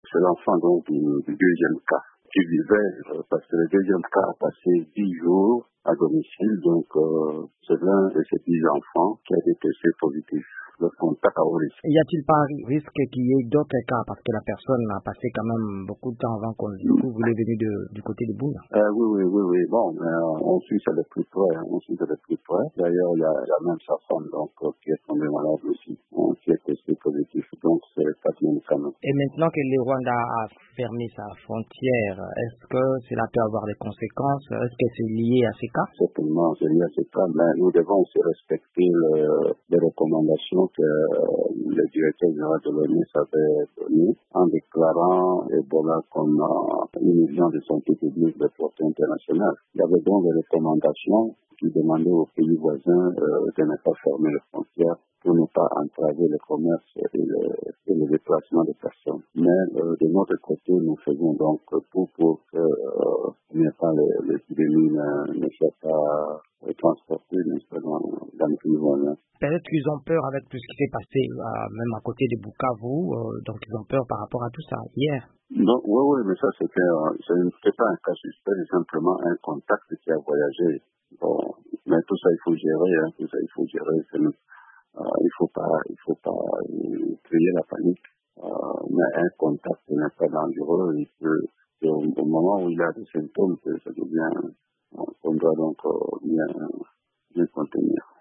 L'analyse de du docteur Jean-Jacques Muyembe, le nouveau coordonnateur de la lutte contre Ebola.